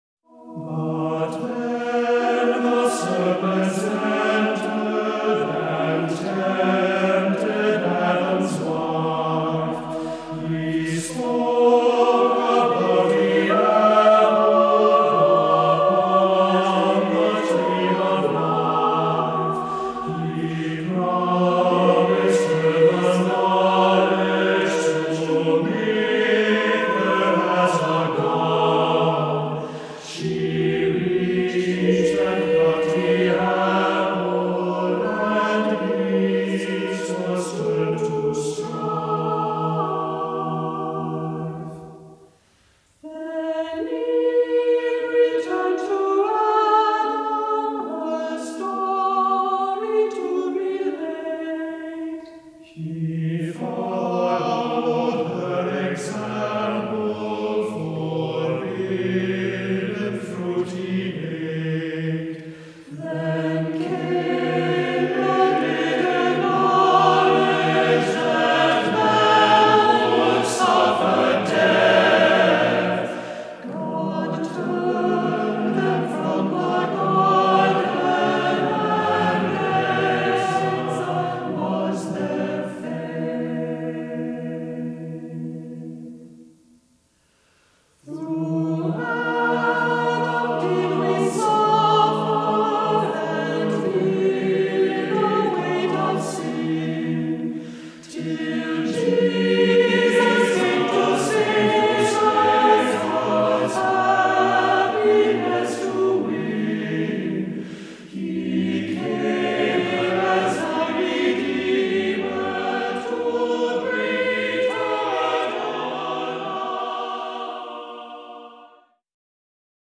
Voicing: a cappella,SATB